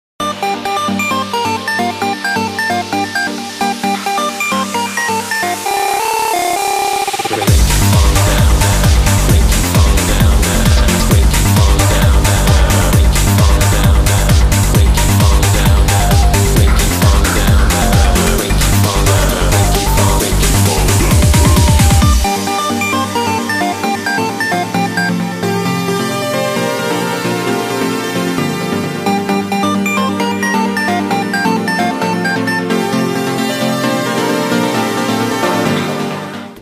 • Качество: 320, Stereo
мужской голос
громкие
dance
Electronic
электронная музыка
красивая мелодия
клавишные
club